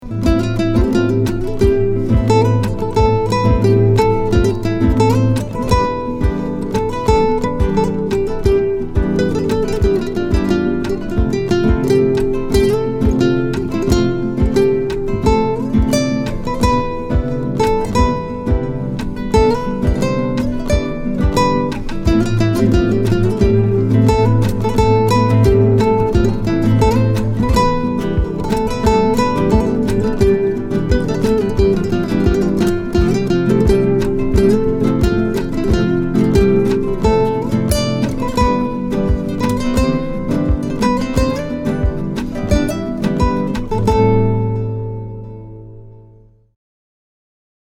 • Качество: 256, Stereo
гитара
без слов
красивая мелодия
струнные